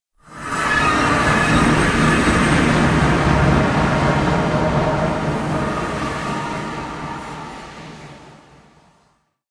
sounds / portal / travel.ogg